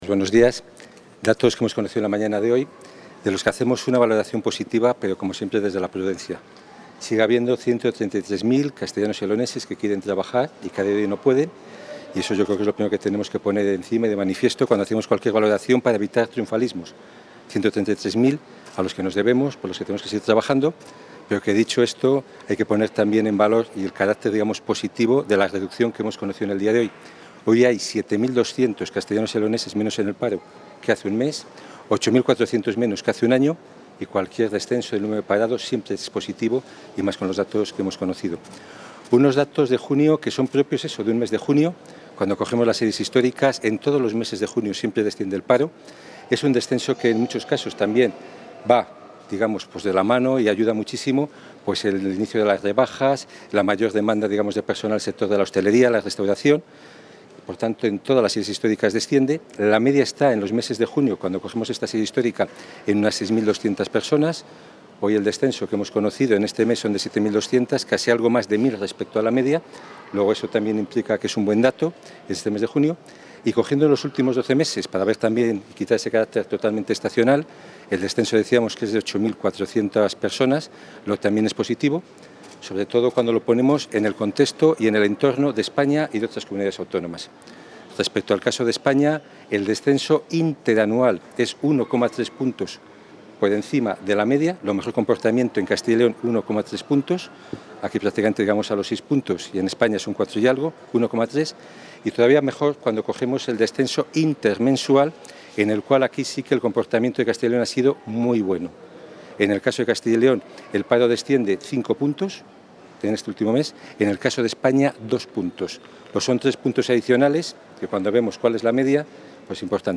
Valoración del viceconsejero.